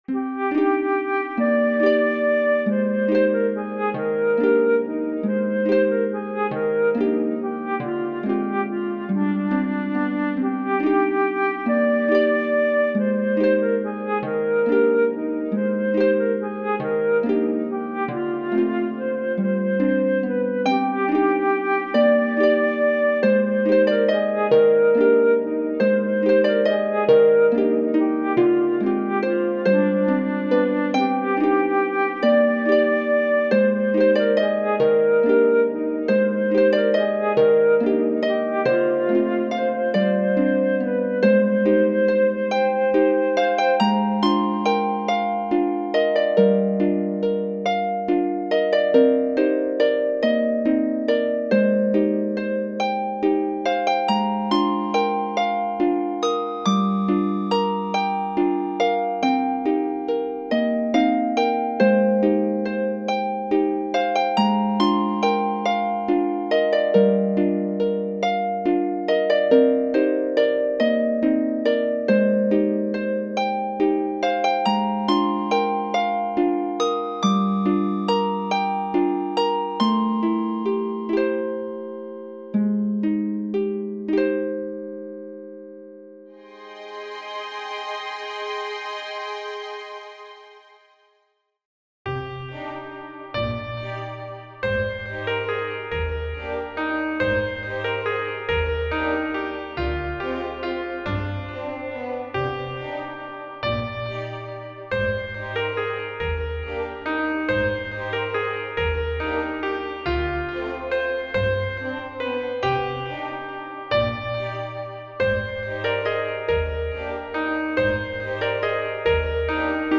切なく寂しいメロディー　シンプル↔オーケストラver.